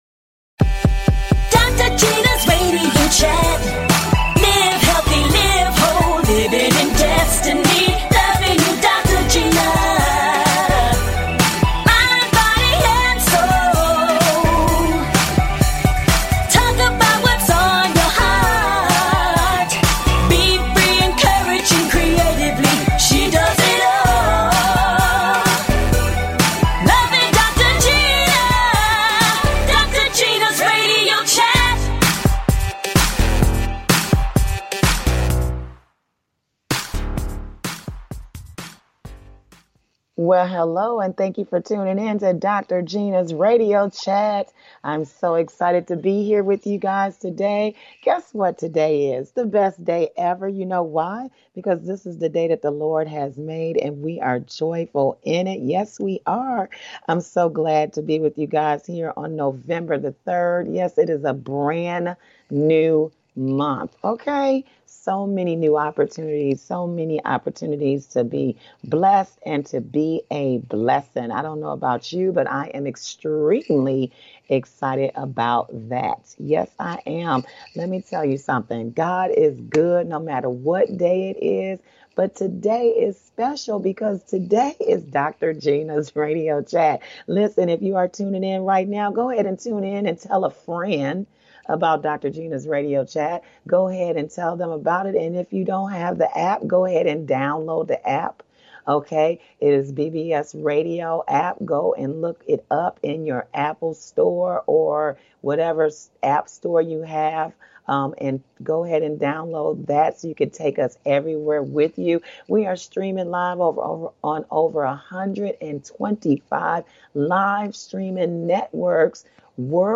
Guest, Dr STELLA IMMANUEL, author